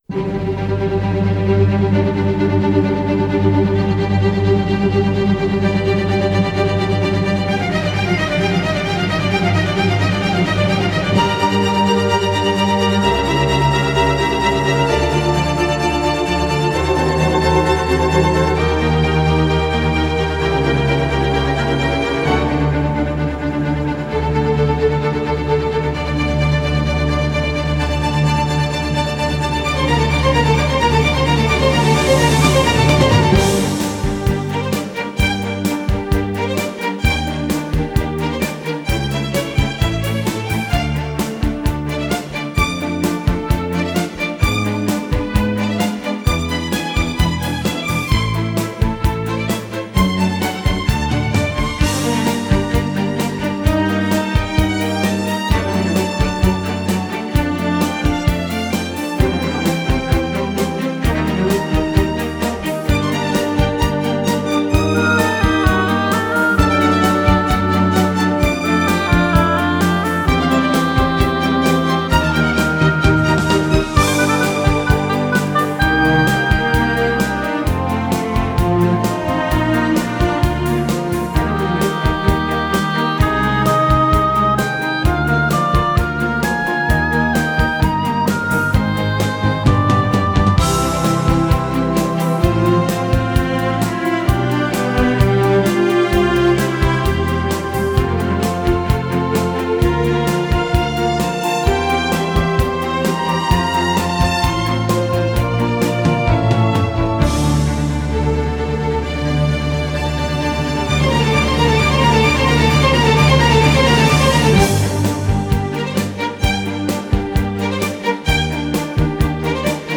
Genre: Classical / Neo Classical